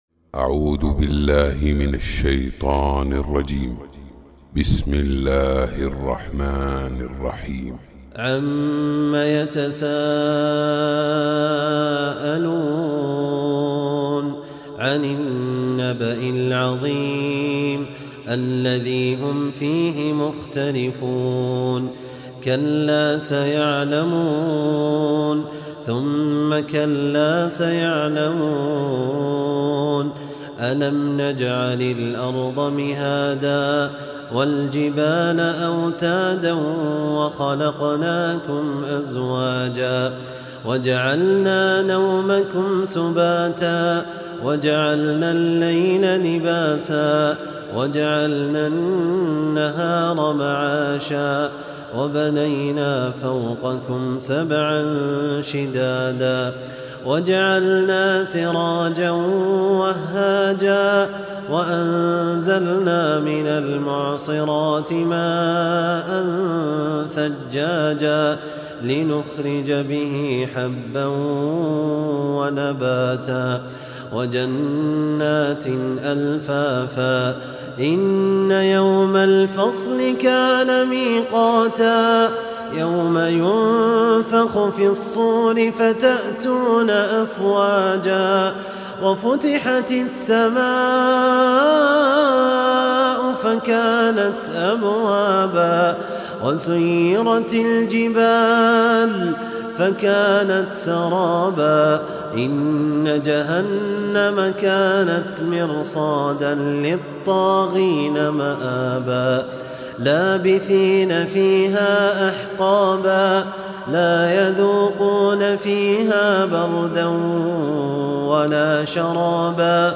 Красивое чтение Корана. Суры с 78 по 114.